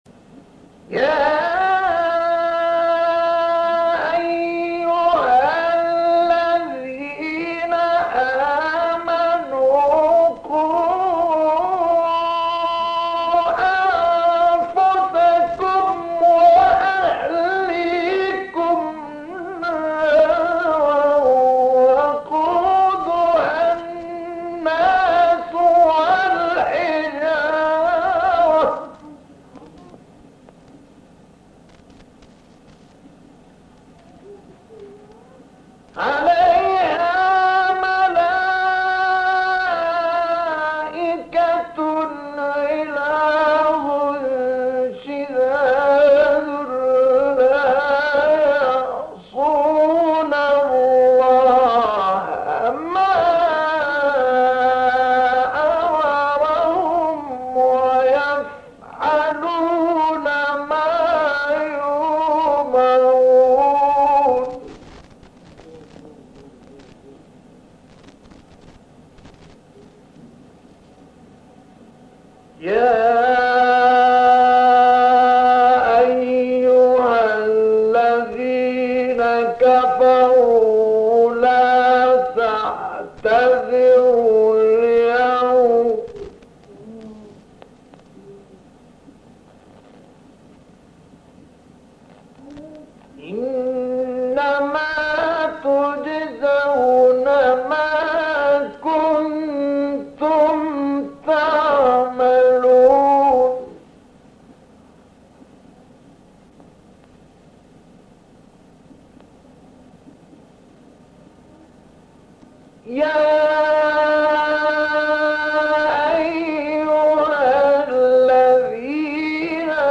گروه شبکه اجتماعی: تلاوت‌های متفاوت آیه 8 سوره مبارکه تحریم با صوت مصطفی اسماعیل را می‌شنوید.
مقطعی از تلاوت در سال 1967 بغداد